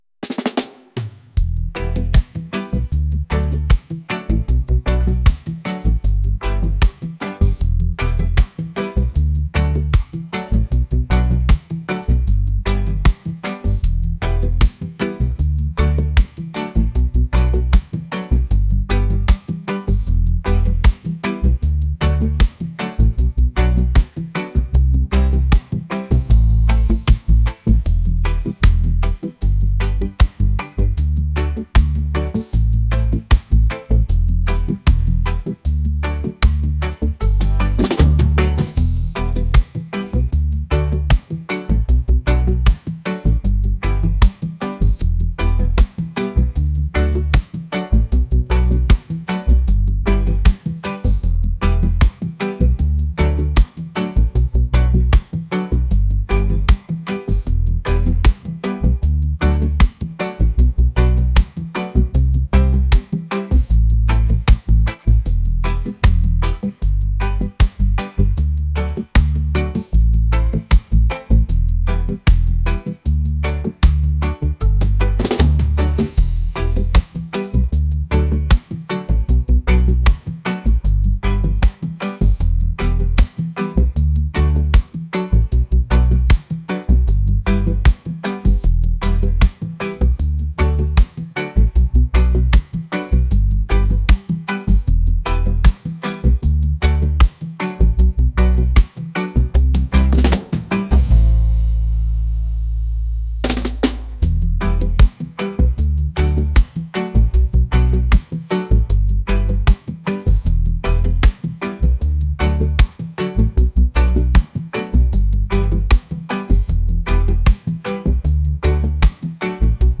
reggae | lounge | lofi & chill beats